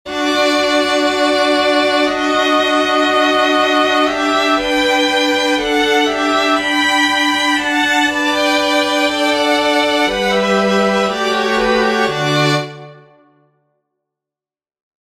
Gathy gibt folgendes Notenbeispiel, in dem im zweiten Takt die erste Violine vom d zum dis wechselt (rot markiert), während Viola und Violoncello weiterhin auf dem Grundton d bleiben: